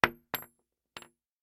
Bullet Shell Sounds
rifle_wood_1.ogg